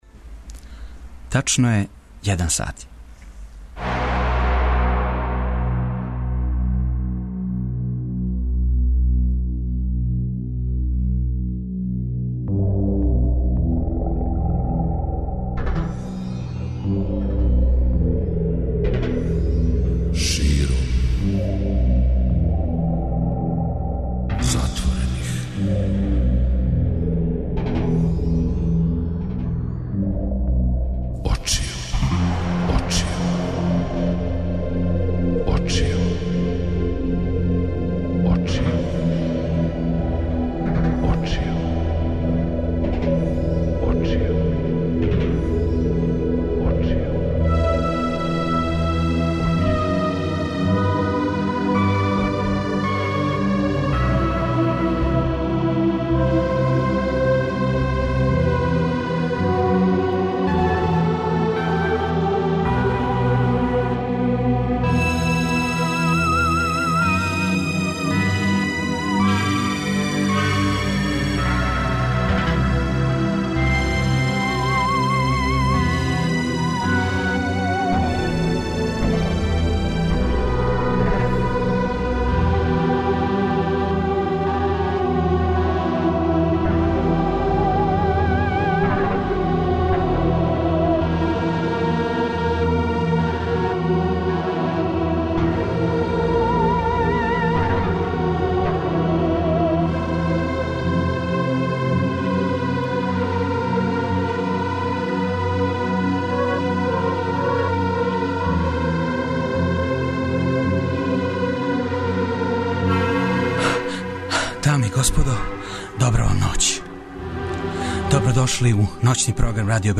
Ноћни програм Двестадвојке је овога пута посвећен смешној страни спорта.